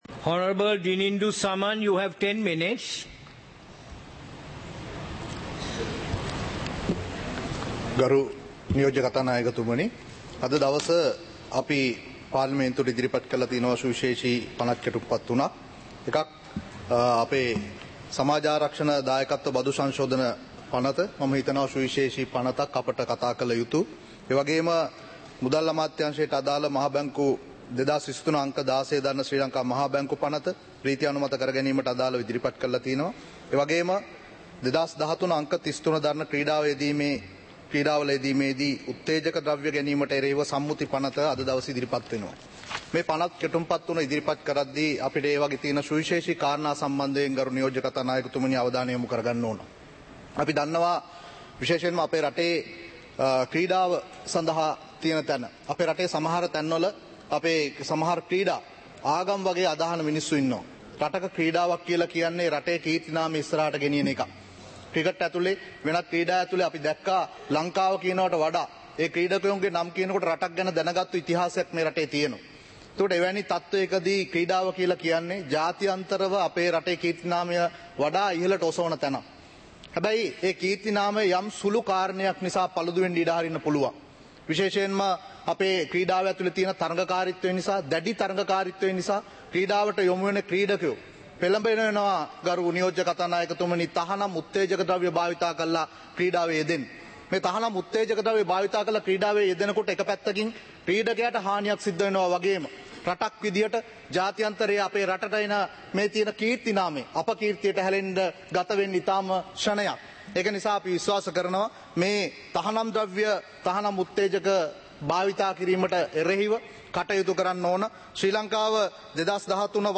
சபை நடவடிக்கைமுறை (2026-04-07)